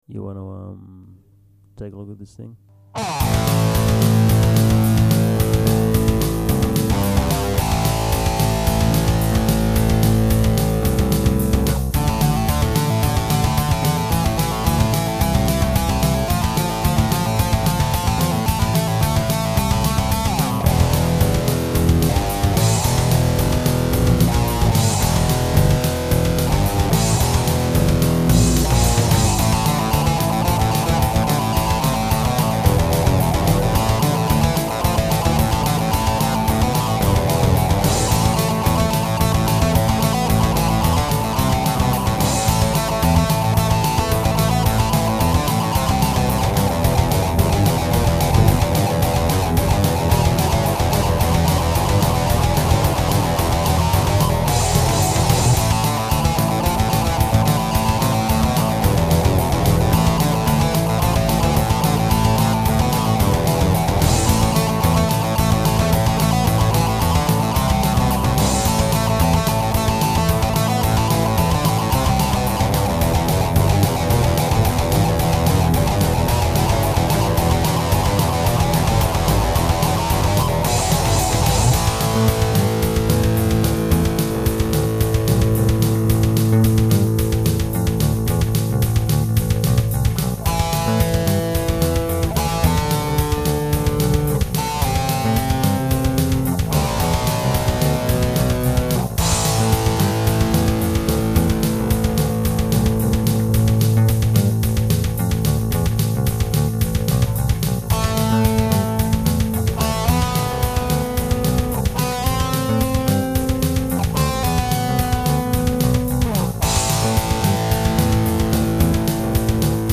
Have a listen to these badly mixed songs I wrote - using Impulse Tracker, my electric guitar, bass and some vocals.